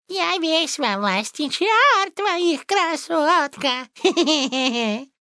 Ария Мортадело.